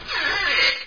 zvuk-skripa-dveri.wav